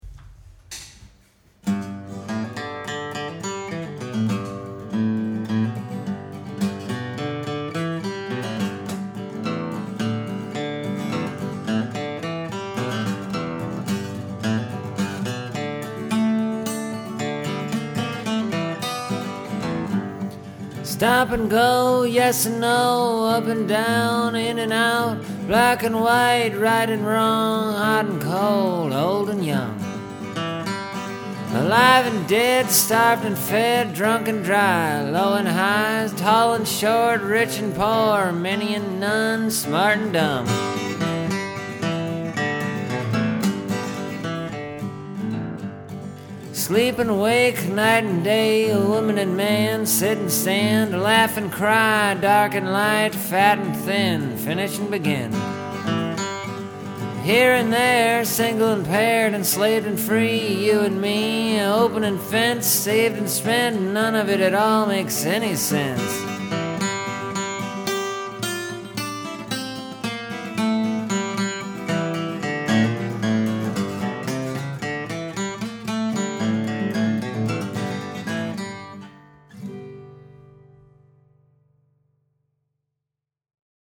Now, this song is kinda in the vein of a talkin’ blues tune.
But it ain’t exactly a talkin’ blues, cause I sing this one really good.